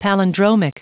Prononciation du mot palindromic en anglais (fichier audio)
Prononciation du mot : palindromic